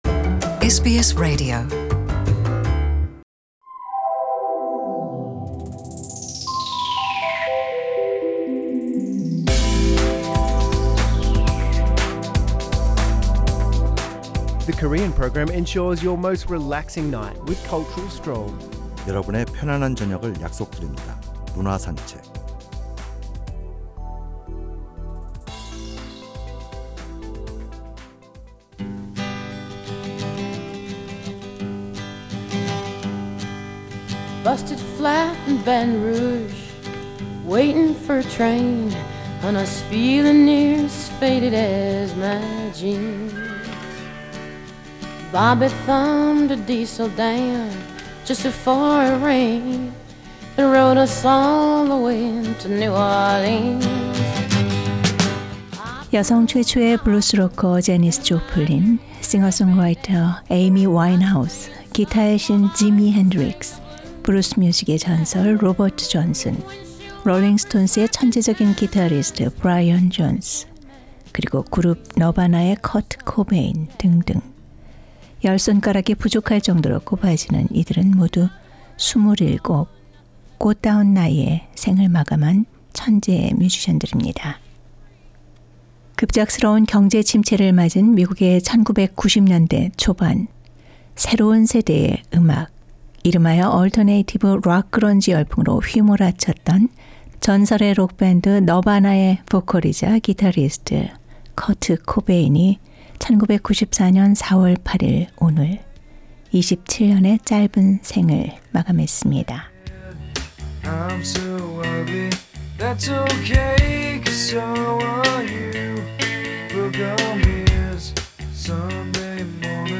The Weekly Culture Odyssey looks into arts and artists' life with background music, and presents a variety of information on culture, which will refresh and infuse with intellectual richness.